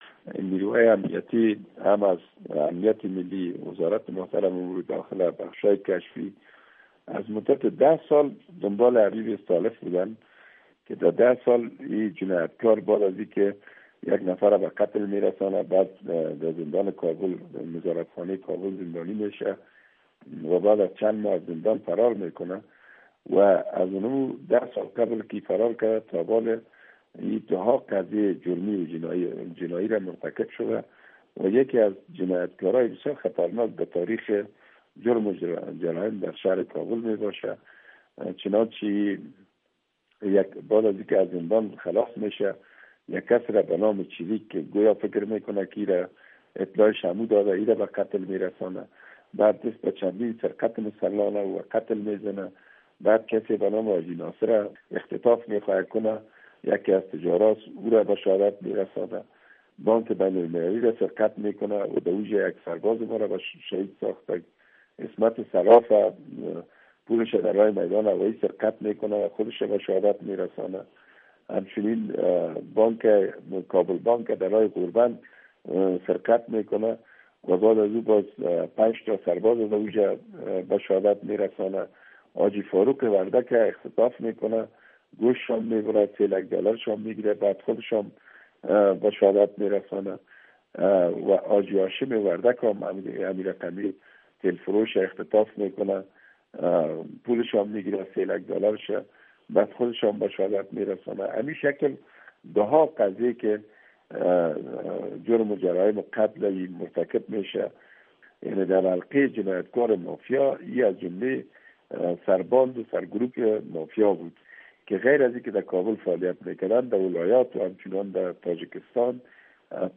interview with Genaral zahir